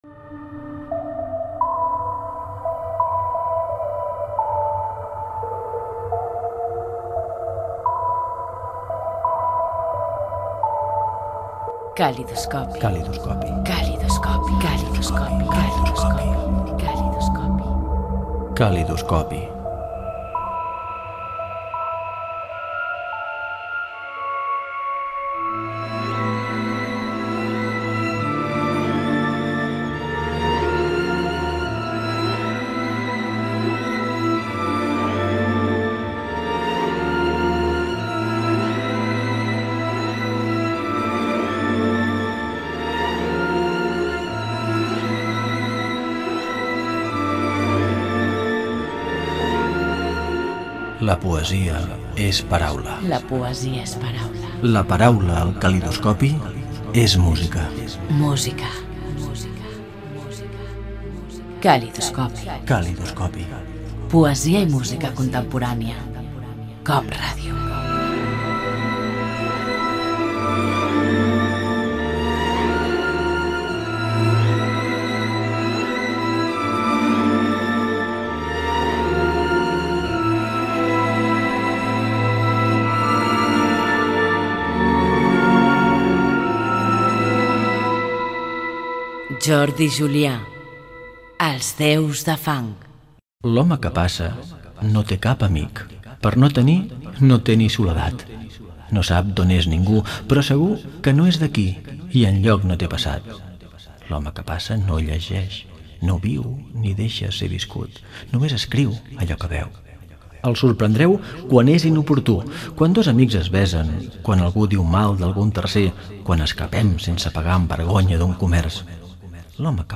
Careta del programa de poesia, paraula i música contemporània. Recitat d'una poesia de Jordi Julià.